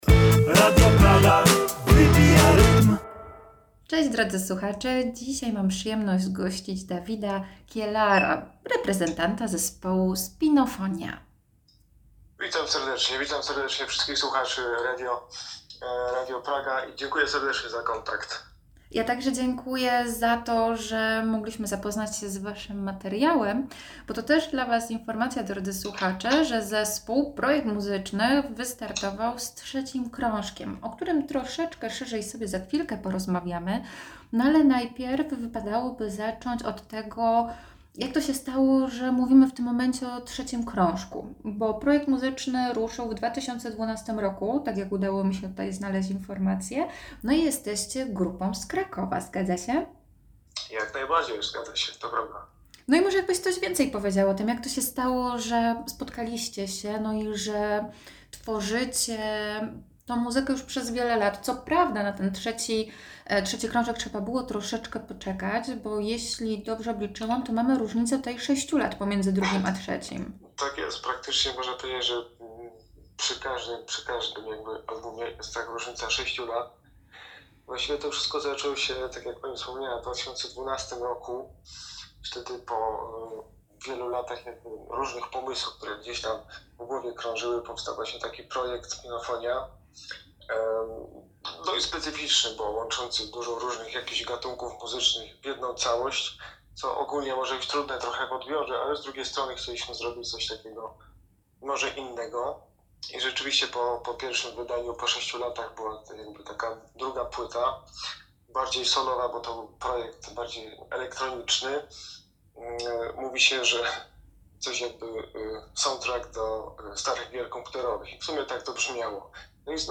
Spinofonia-wywiad-2025-.mp3